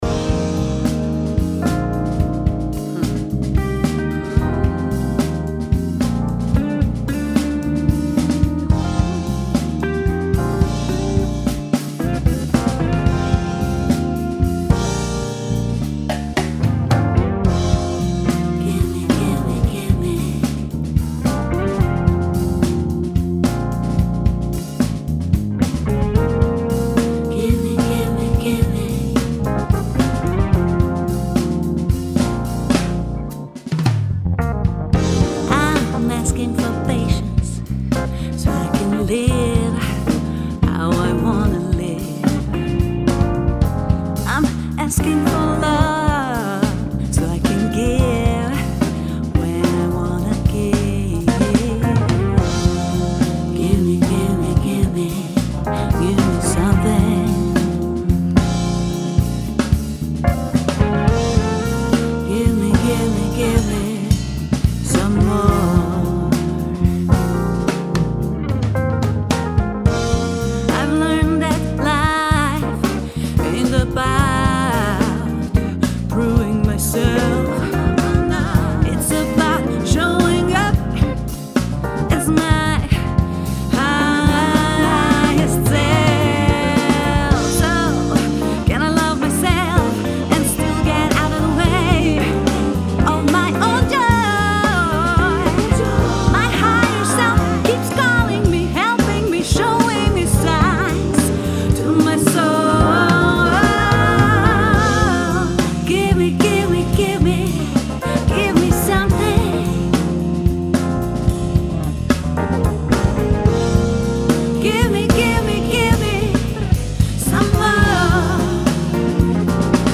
Vokal
klavir
bubanj